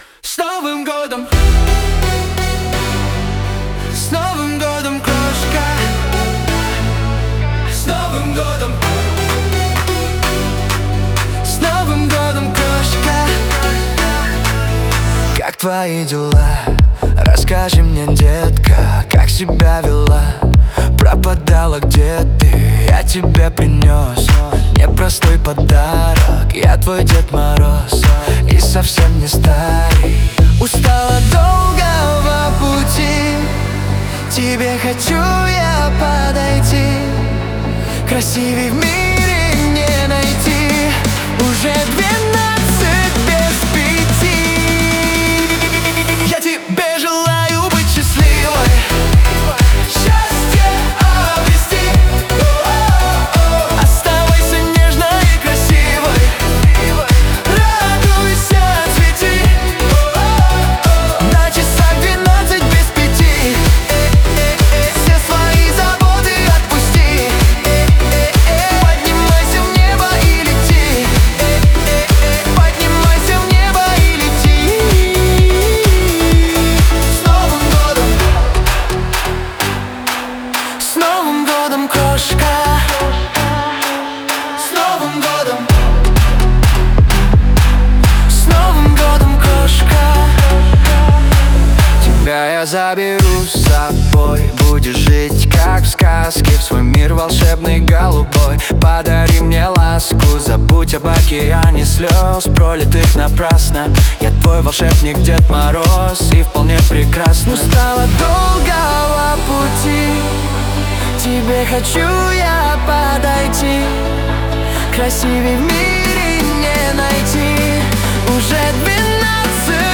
заводные треки